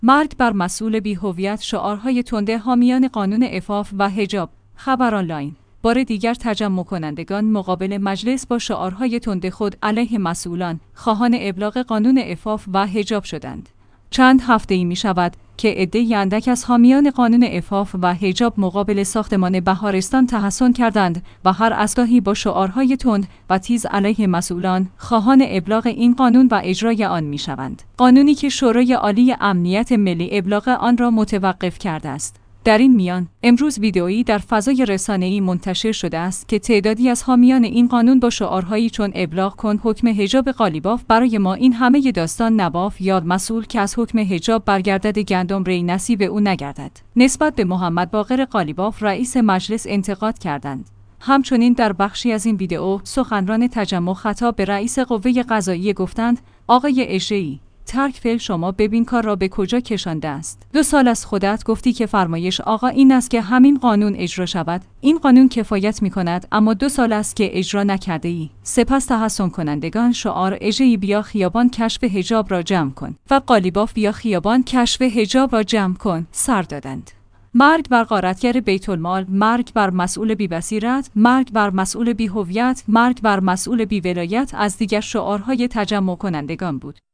مرگ بر مسئول بی هویت؛ شعارهای تند حامیان قانون عفاف و حجاب
خبرآنلاین/ بار دیگر تجمع کنندگان مقابل مجلس با شعارها تند خود علیه مسئولان، خواهان ابلاغ قانون عفاف و حجاب شدند.